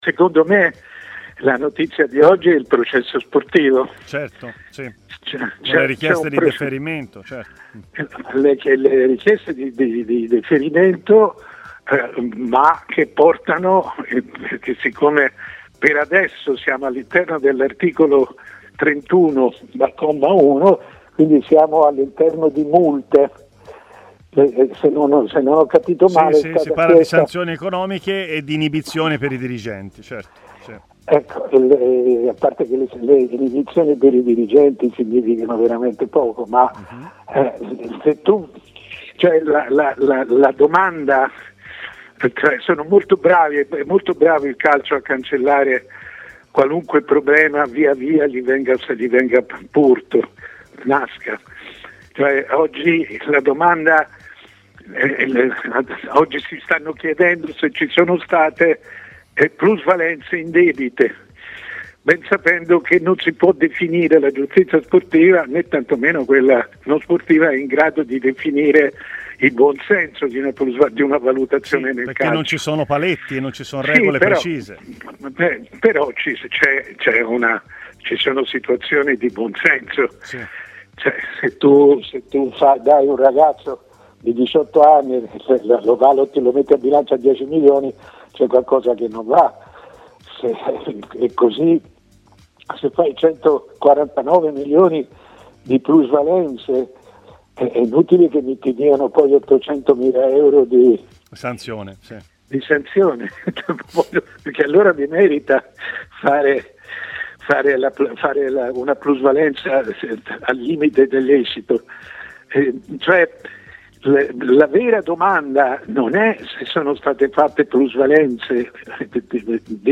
Mario Sconcerti, grande firma del giornalismo sportivo e opinionista è intervenuto ai microfoni di Tmw Radio: "La vera notizia di oggi è quella sulle plusvalenze indebite.